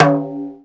727 Timbale Lo.wav